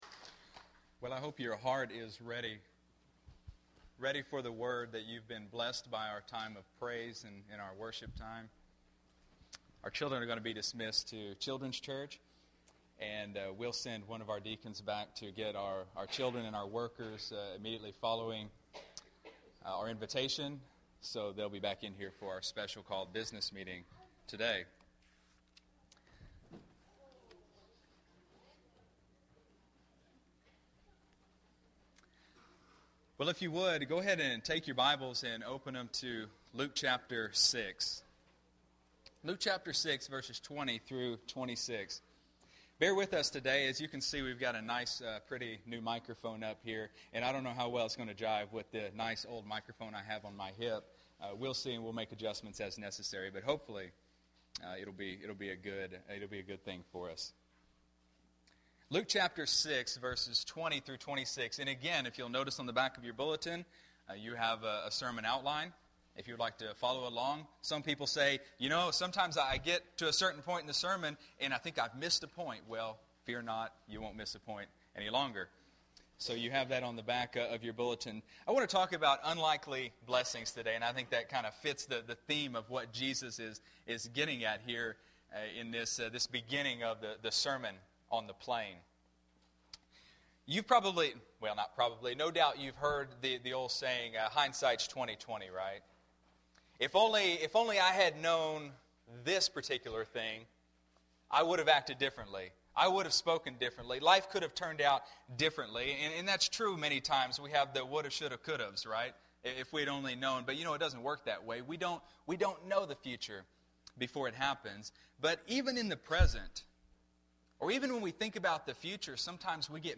Sunday, March 9, 2014 (Sunday Morning Service)